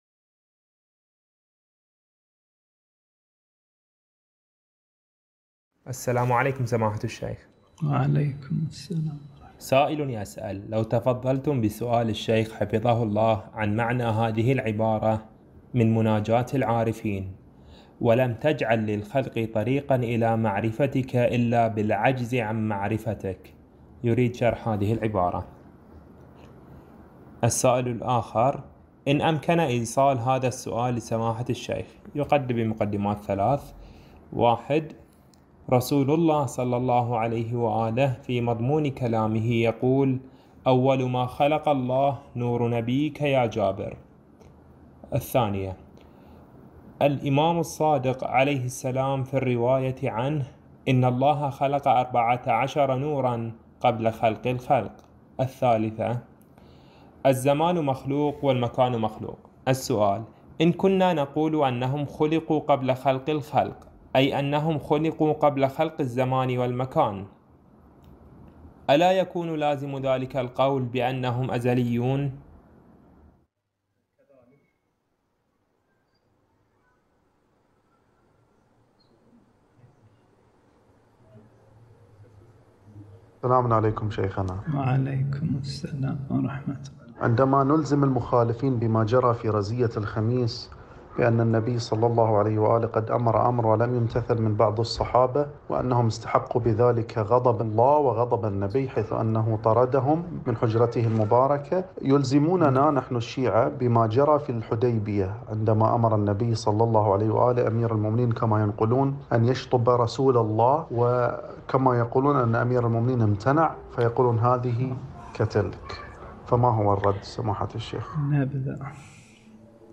درس ليلة السبت 20 شهر شعبان 1442 هـ